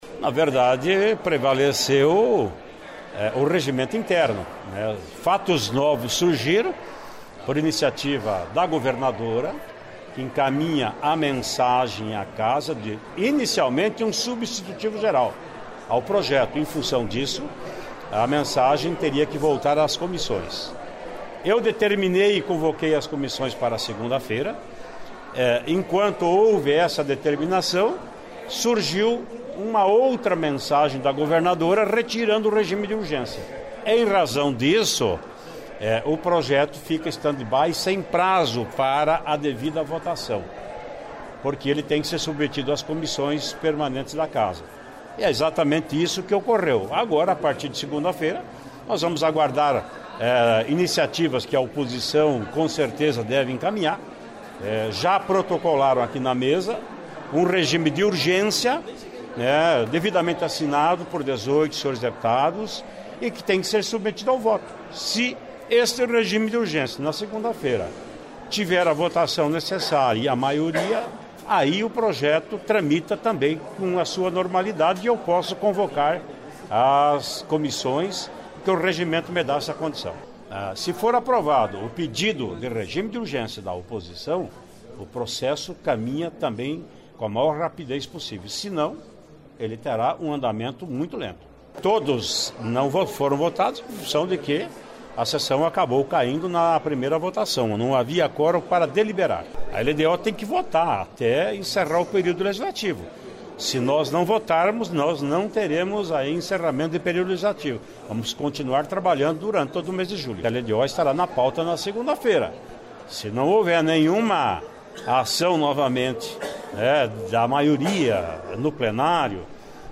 Ouça a entrevista do presidente da Assembleia Legislativa, deputado Ademar Traiano (PSDB), logo após o fim da sessão plenária desta quarta-feira (4), onde todos os ítens da pauta acabaram não sendo votados por falta de quórum. Ele explica como será a votação da LDO e a da nova proposta do Governo com a reposição para os servidores.
(Sonora)